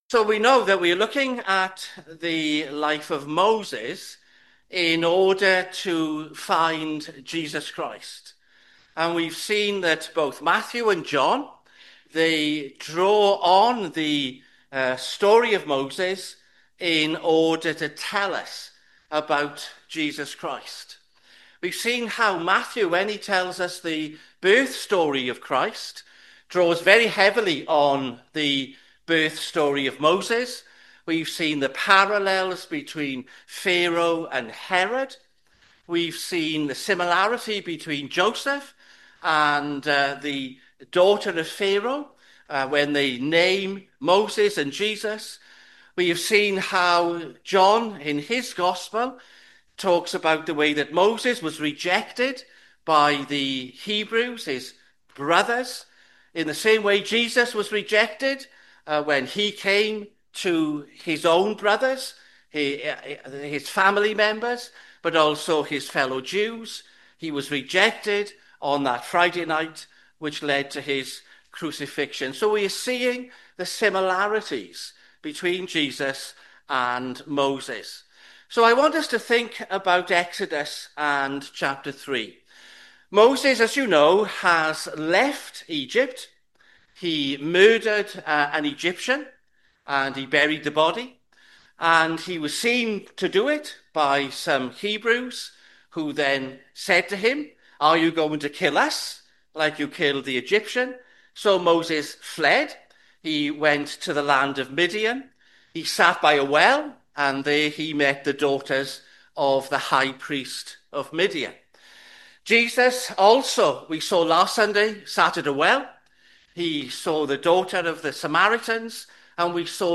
Sermons